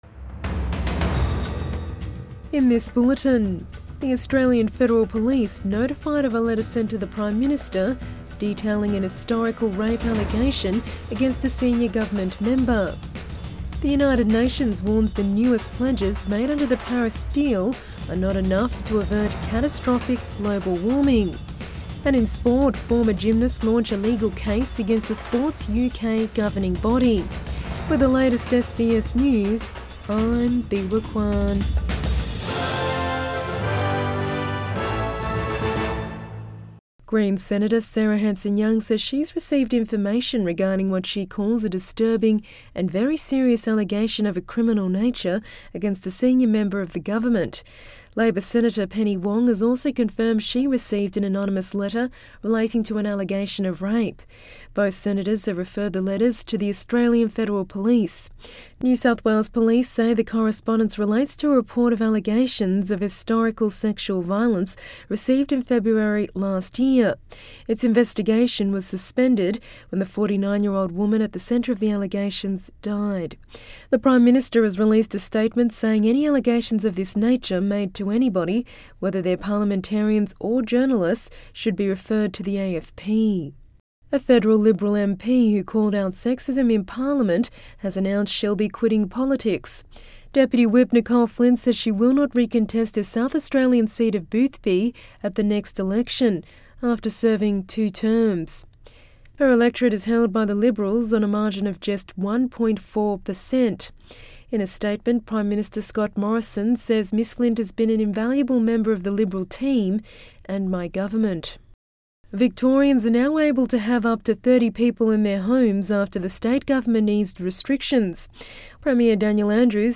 AM bulletin 27 February 2021